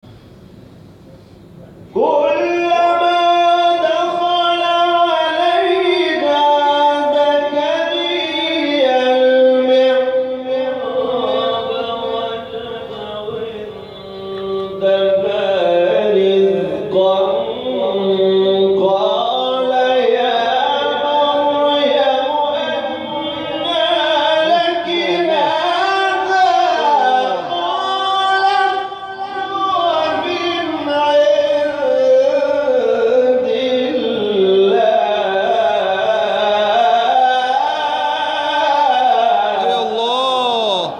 گروه فعالیت‌های قرآنی: جدیدترین مقاطع صوتی تلاوت شده توسط قاریان ممتاز کشور را می‌شنوید.